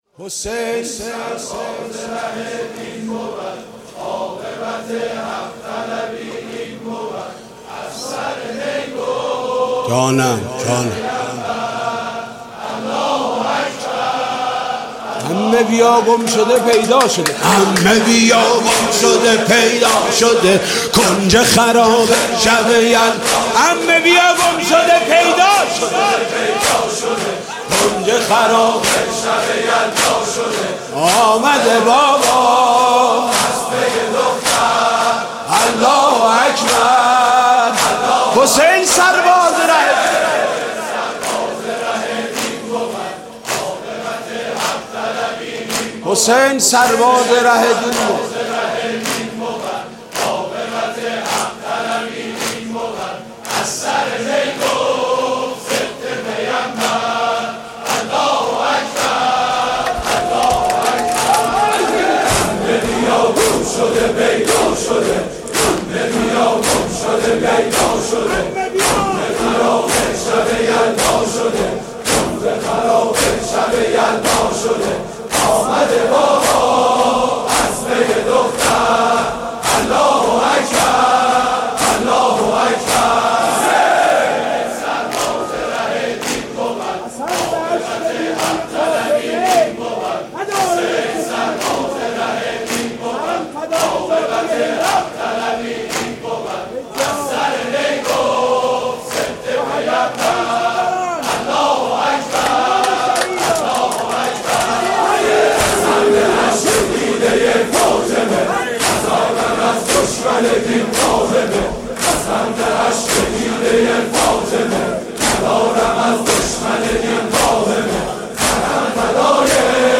مداحی شب سوم محرم 96 با نوای مداحان اهل بیت (ع)